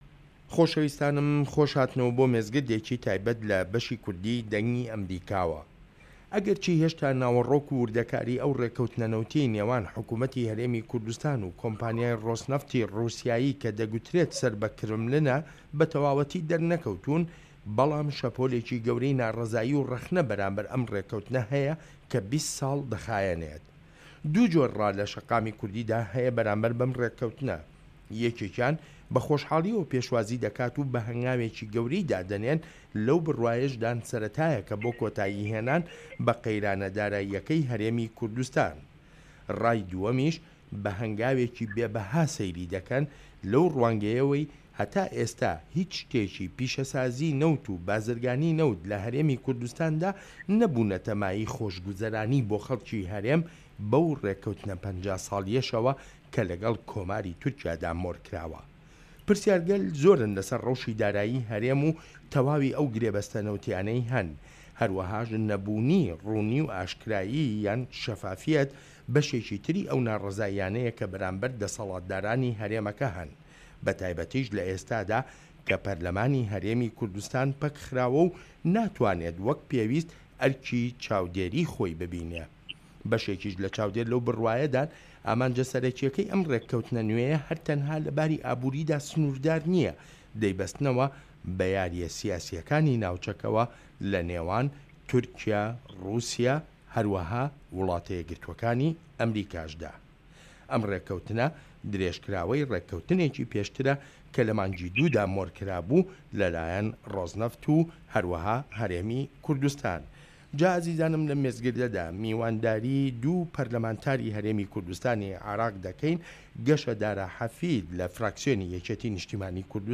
مێزگرد: نەوت لە سیاسەتی حکومەتی هەرێمی کوردستاندا